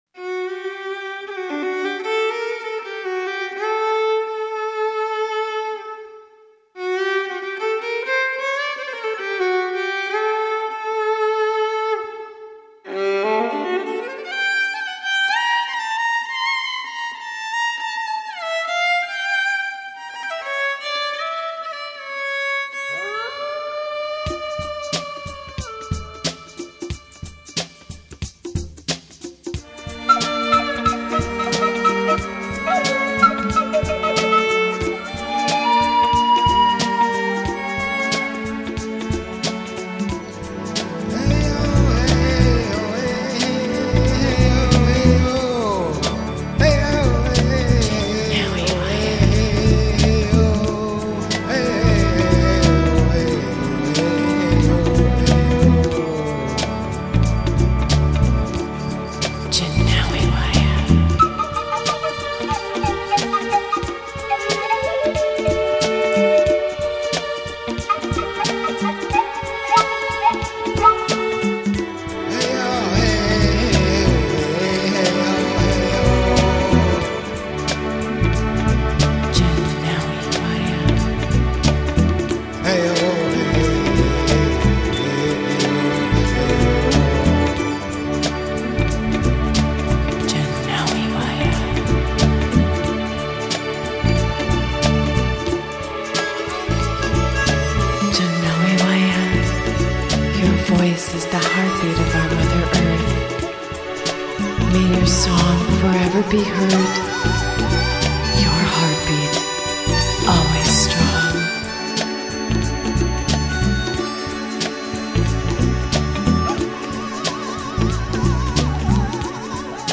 99年终最感动人心的狼族音乐